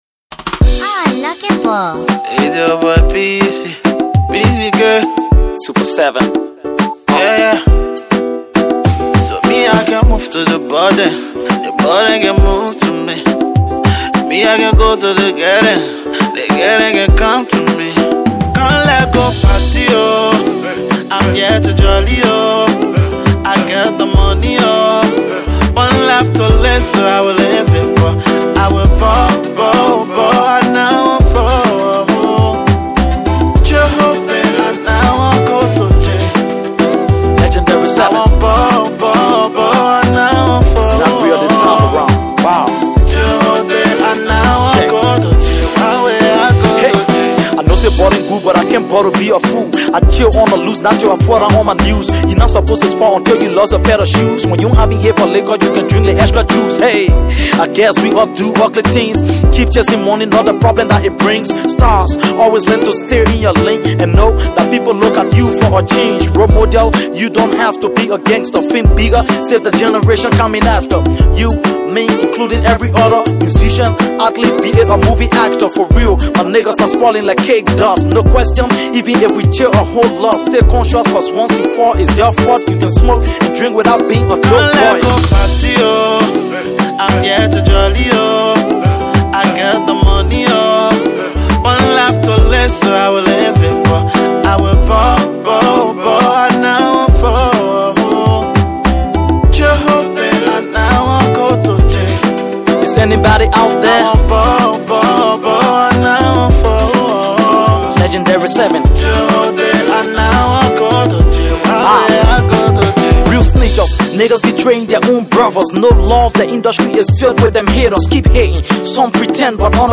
/ Hip-Co, Hip-Hop / By